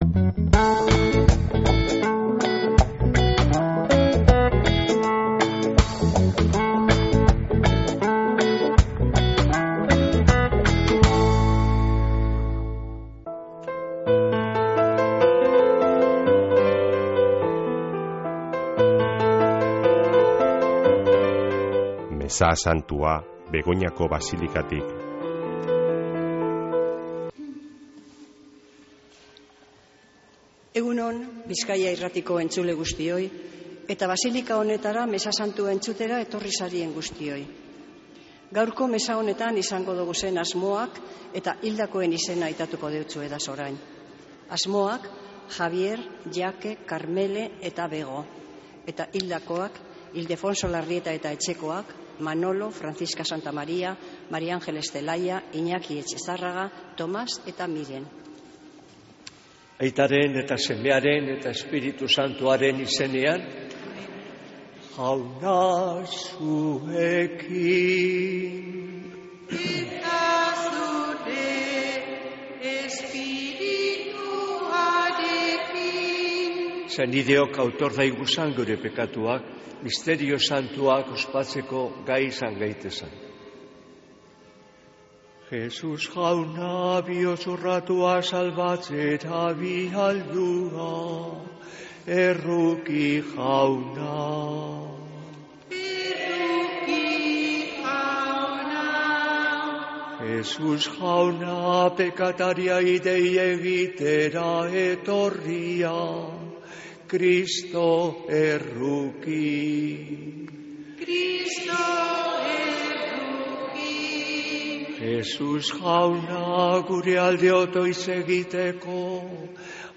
Mezea Begoñako Basilikatik | Bizkaia Irratia
Mezea (26-03-17)